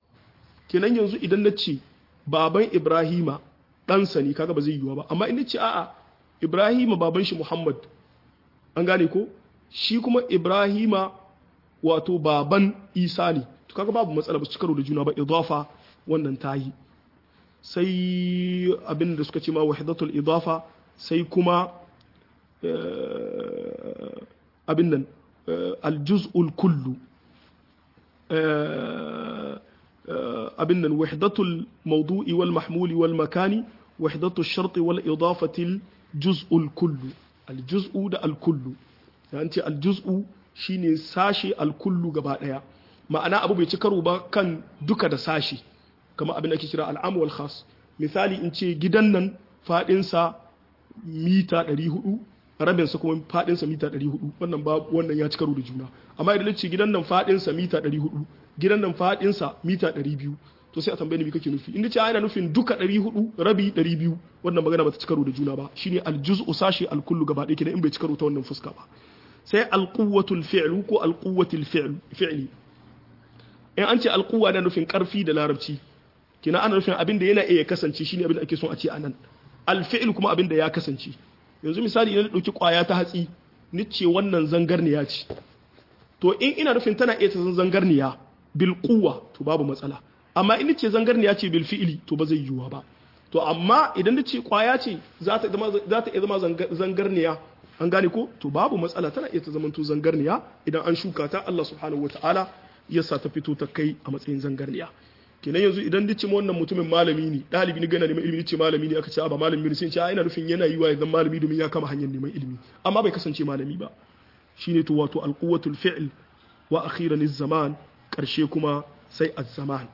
Abinda mai tafsir Yakamata ya sani-2 - MUHADARA